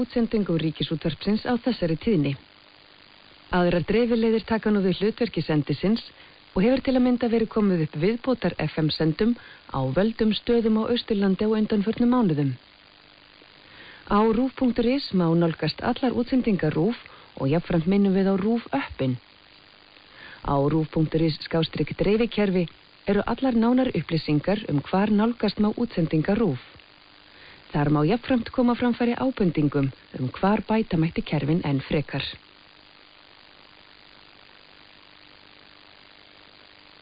This is the goodbye message from 207 kHz, Eidar (RÚV á Eiðum)